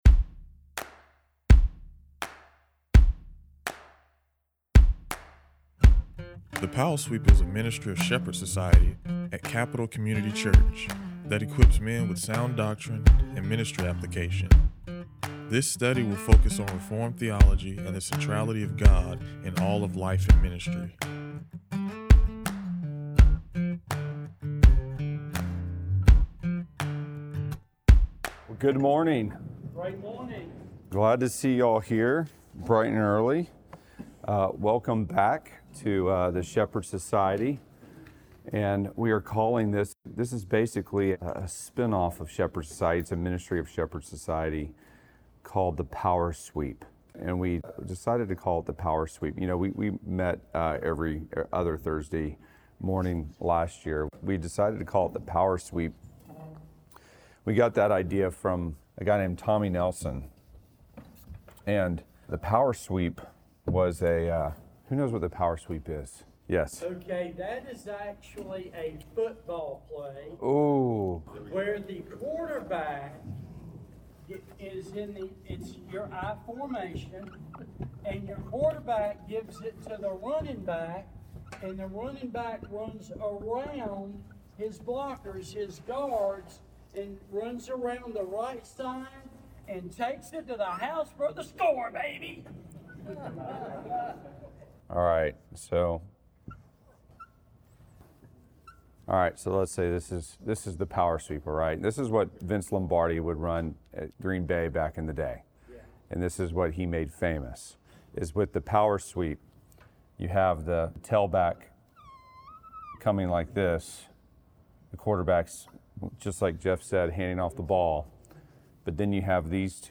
Capital Community Church Sermons podcast